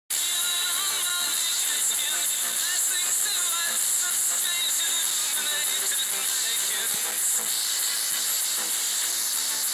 Das lesen von der SD-Karte funktioniert - aber das Abspielen ist noch etwas unsauber im Klang.
Timer2 läuft mir der passenden Datenrate, da die Musik von der Tonhöhe und Abspielgeschwindigkeit passt. Im Anhang ein Klangbeispiel von meiner AD-Wandlung.